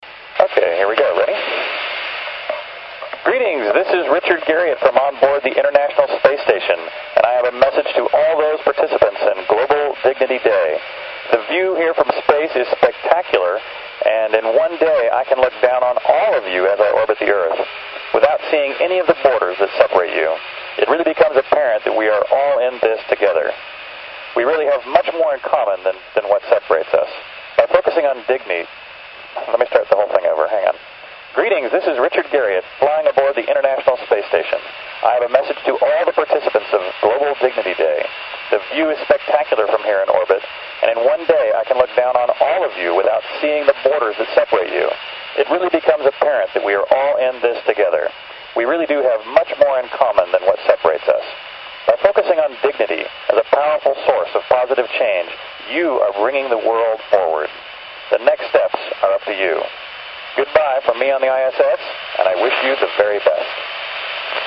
Космический турист Ричард Гэрриот с борта МКС произносит речь в честь Global Dignity Day (Oct, 20)
Начало » Записи » Записи радиопереговоров - МКС, спутники, наземные станции
МКС, виток 2008-10-18 14:05-14:09 над С-Петербургом. 143.625 МГц.
Ричард Гэрриот с борта Международной Космической Станции произосит речь в честь Global Dignity Day (20 октября).